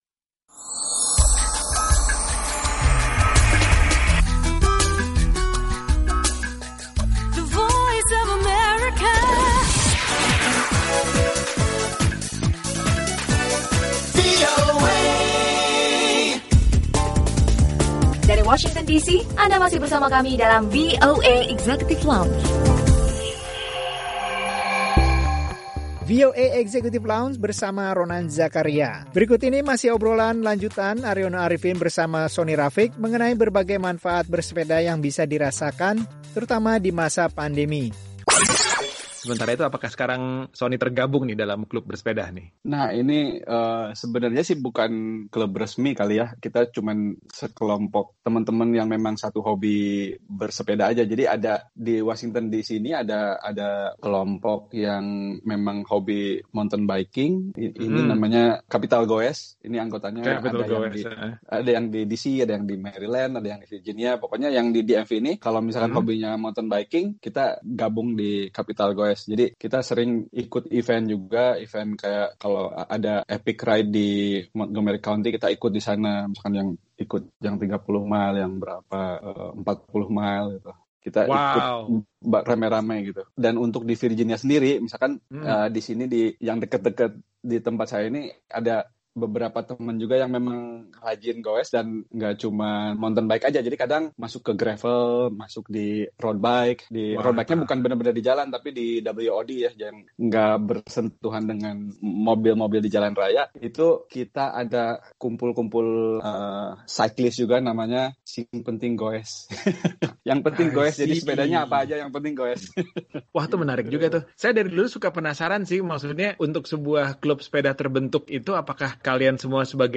Obrolan ringan